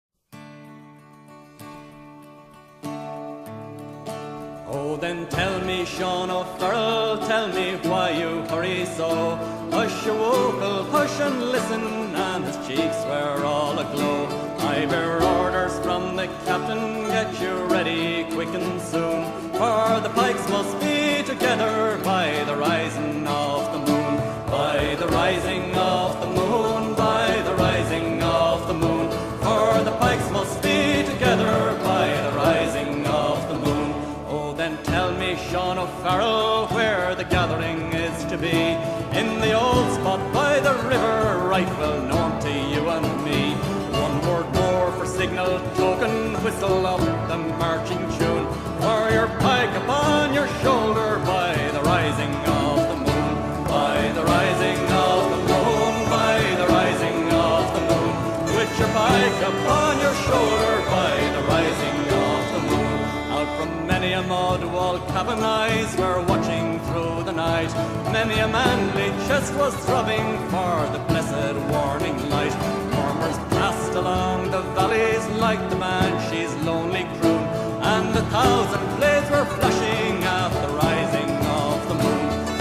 irish-folk-song-the-rising-of-the-moon-mp3cut.net_.mp3